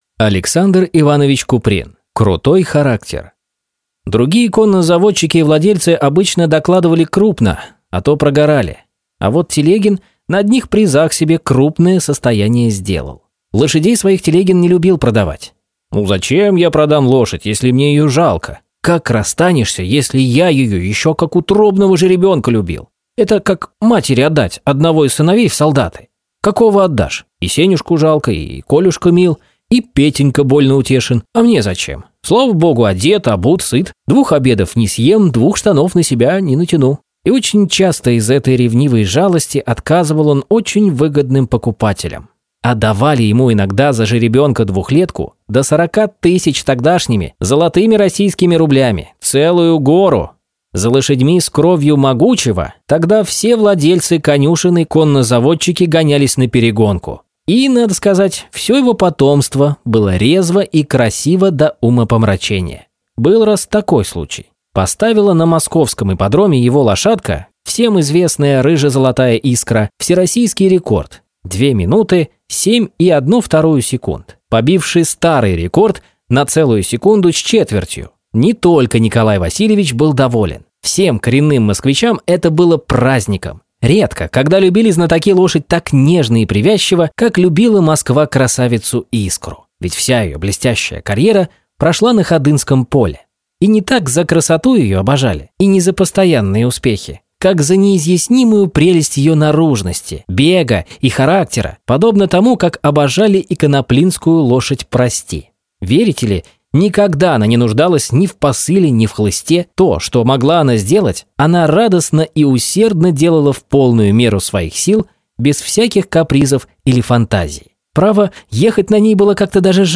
Аудиокнига Крутой характер | Библиотека аудиокниг